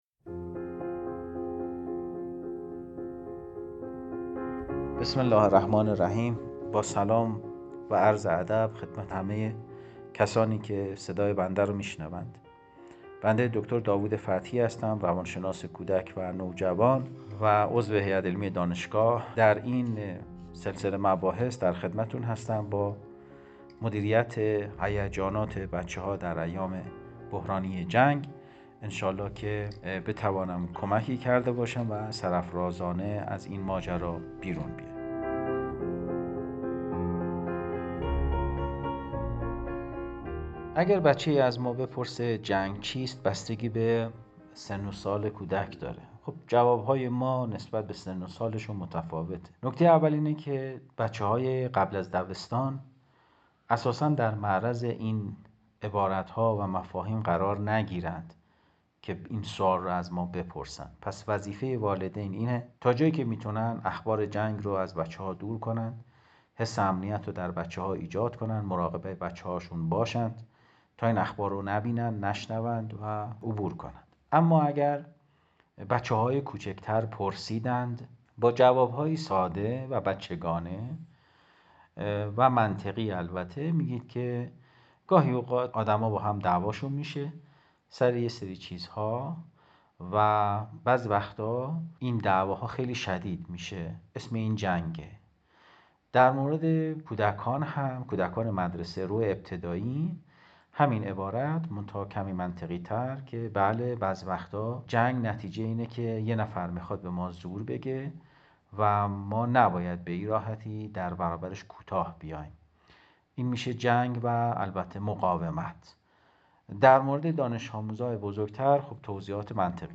گوینده کارشناس